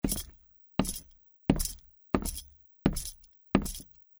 带有金属链的皮靴在木板地上行走-中频YS070525.mp3
通用动作/01人物/01移动状态/木质地面/带有金属链的皮靴在木板地上行走-中频YS070525.mp3
• 声道 立體聲 (2ch)